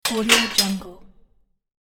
افکت صدای اهرم سوئیچ ماشین 2
Sample rate 16-Bit Stereo, 44.1 kHz
Looped No